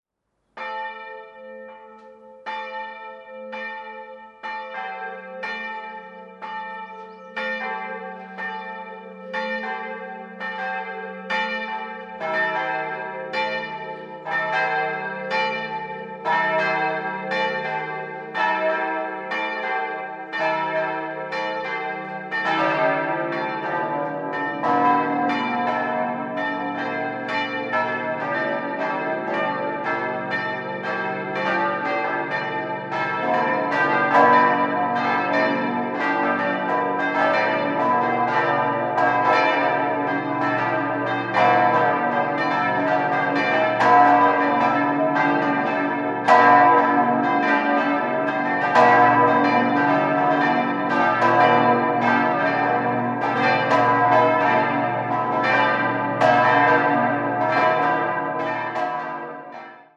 Der mächtige Baukörper bildet das zweithöchste Kirchengebäude (auf die Innenraumhöhe bezogen) im Bistum Regensburg und wurde in Form eines griechischen Kreuzes errichtet mit einem niedrigen, davor gestellten Glockenturm. 5-stimmiges Gloria-TeDeum-Geläute: h°-d'-e'-g'-a' Die Glocken wurden im Jahr 1947 von Johann Hahn in Landshut gegossen.